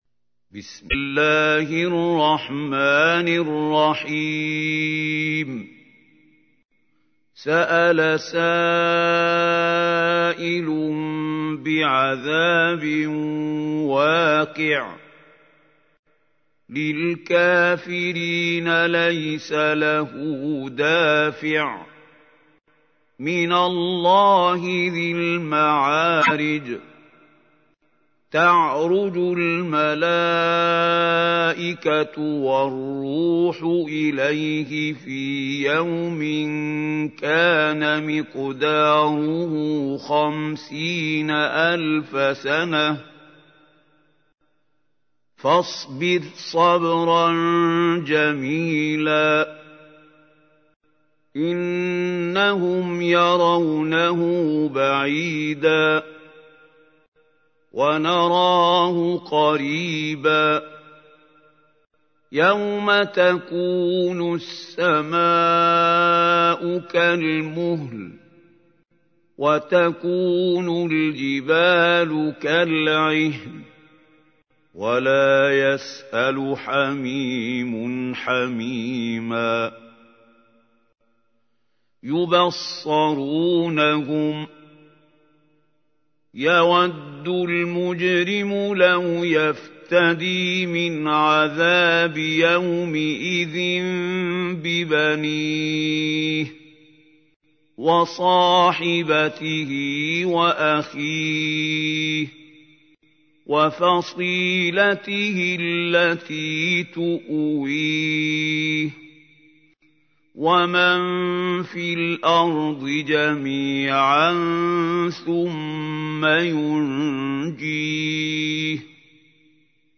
تحميل : 70. سورة المعارج / القارئ محمود خليل الحصري / القرآن الكريم / موقع يا حسين